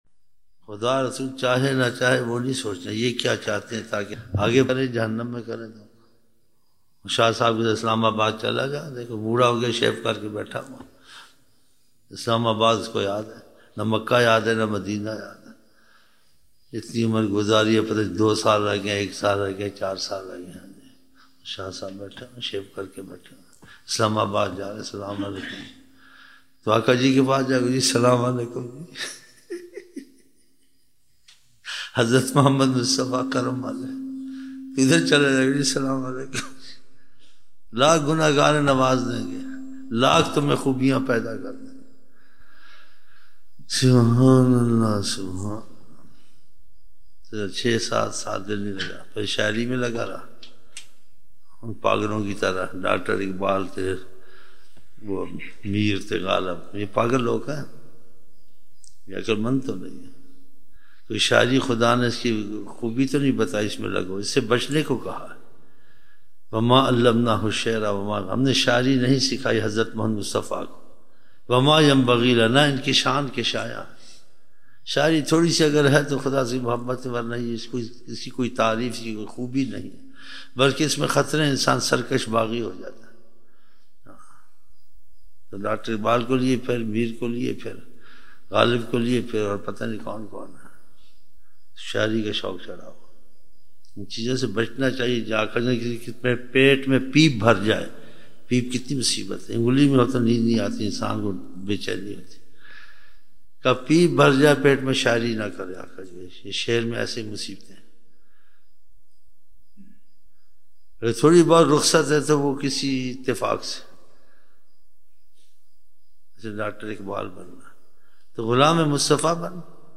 11 November 1999 - Thursday Fajar mehfil (3 Shaban 1420)